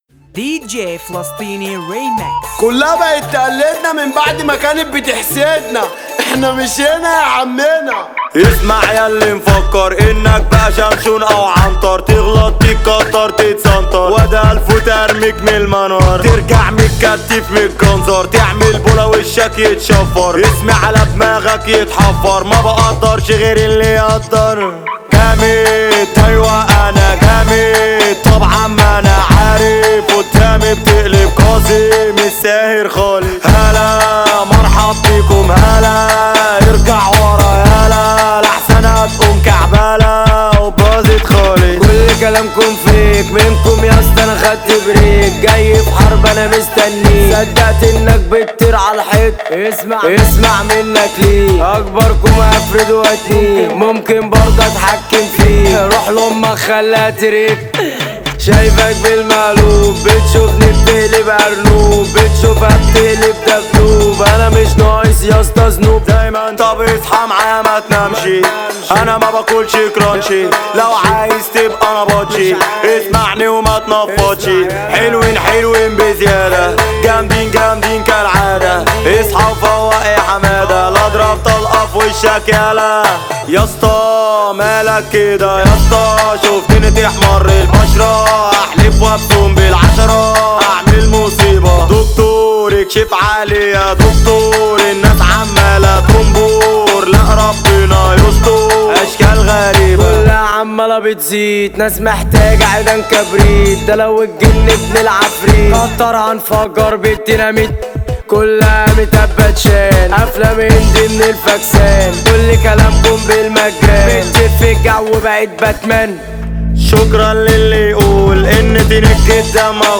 اغاني شعبي ومهرجانات